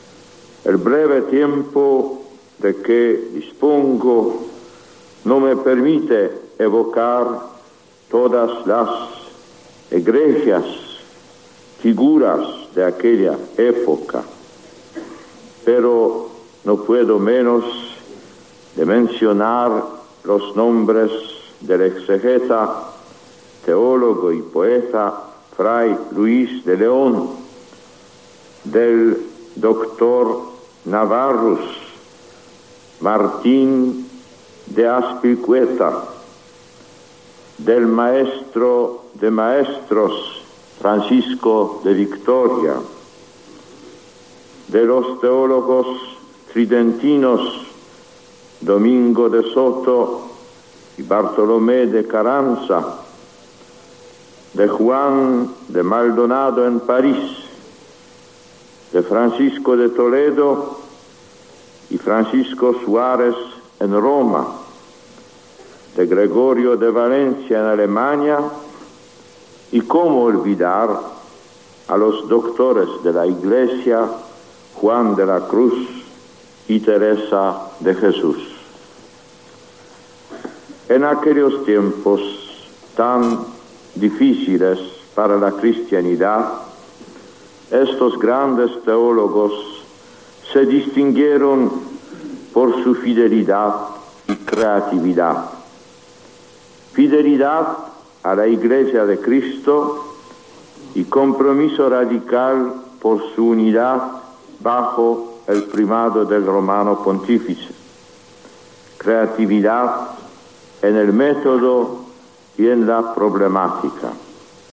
Discurso del Papa Juan Pablo II a los profesores de teología de la Universidad Pontificia de Salamanca